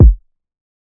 Shlammed Kick.wav